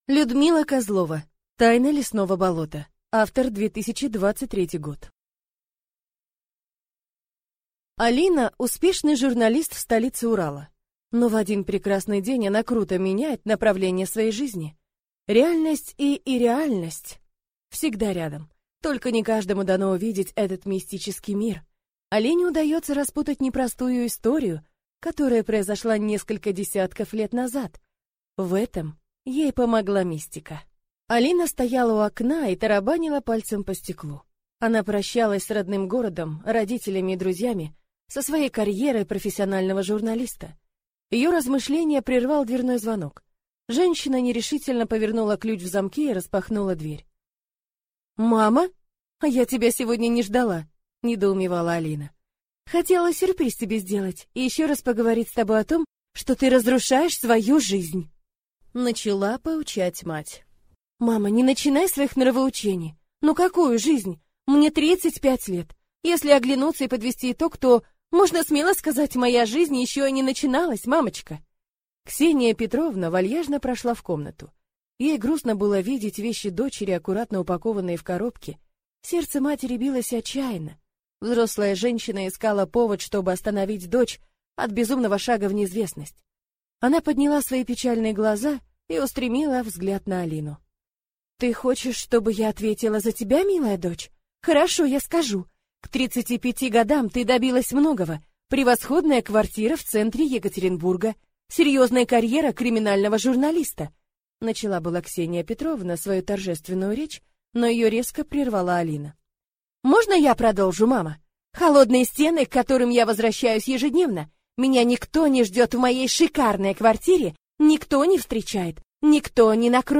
Аудиокнига Тайна лесного болота | Библиотека аудиокниг